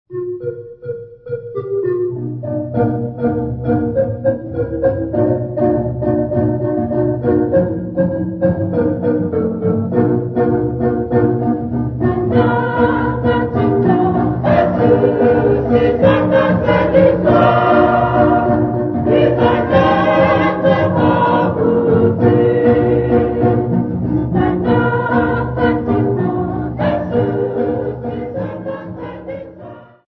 Zwelitsha Church Congregation
Folk music
Sacred music
Field recordings
Africa South Africa Zwelitsha sa
sound recording-musical
Catholic church hymn, accompanied by the marimba xylophone.